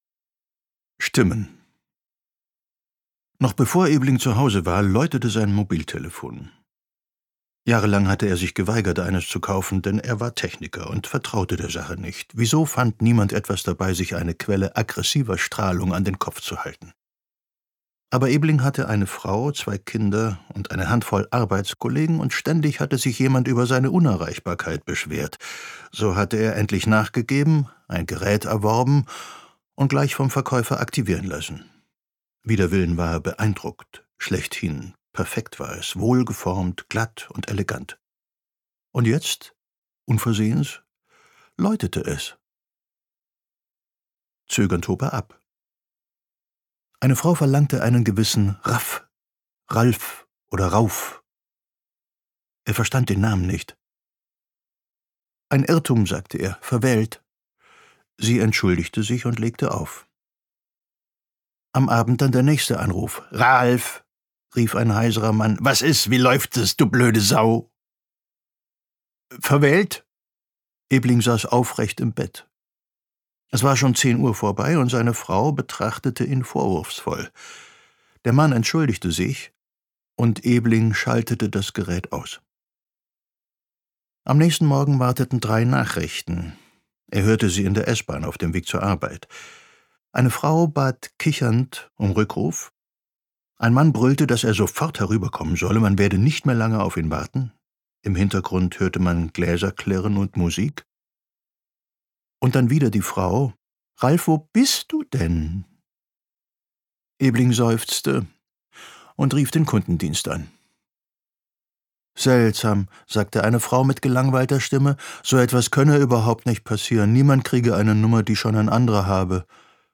Gekürzt Autorisierte, d.h. von Autor:innen und / oder Verlagen freigegebene, bearbeitete Fassung.
Ruhm Gelesen von: Ulrich Noethen